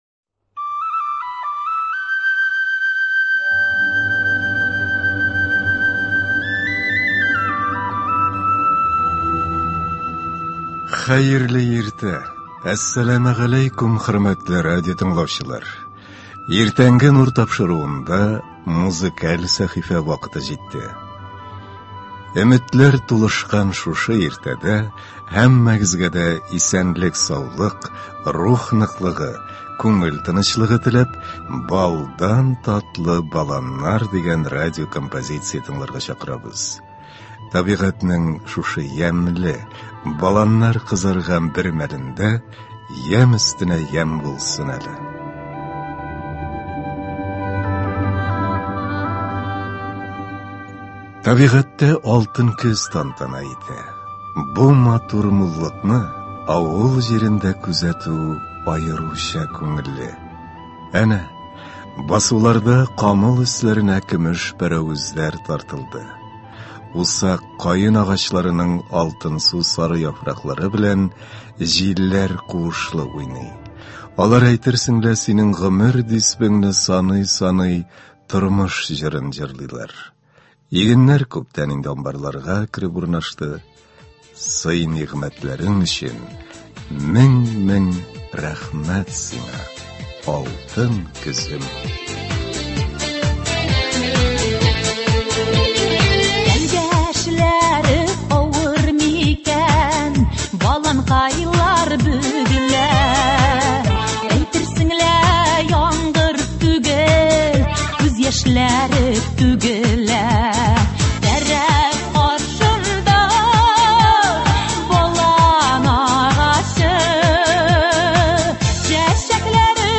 Өметләр тулышкан шушы иртәдә һәммәгезгә дә исәнлек-саулык, рух ныклыгы, күңел тынычлыгы теләп, “Балдан татлы баланнар” дигән радиокомпозиция тыңларга чакырабыз.